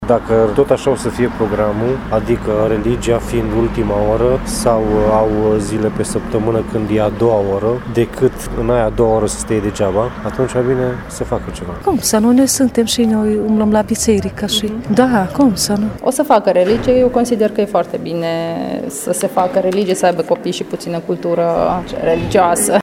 Părinţii nu sunt de acord cu eliminarea religiei din şcoli, fie pentru că vor ca cei mici să aibă o educaţie morală, fie pentru că nu ştiu ce să facă cu ei pe durata acelei ore: